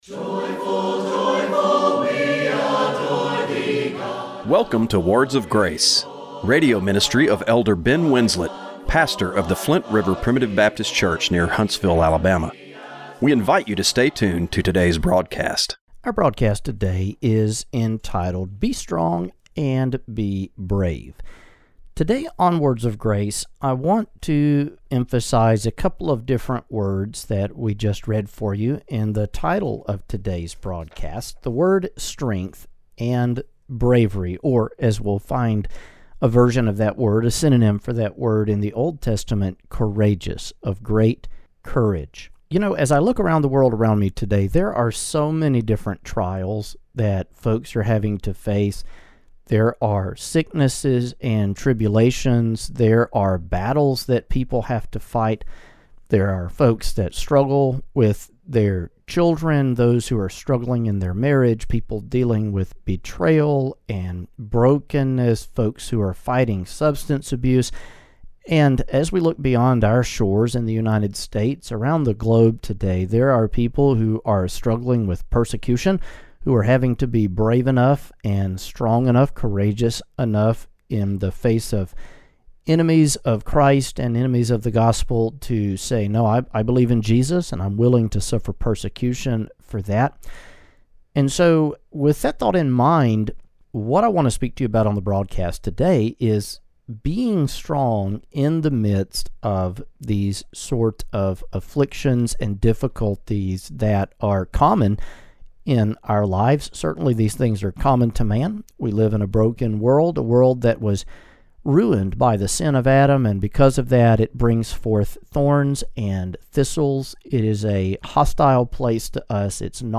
Radio broadcast for September 7, 2025.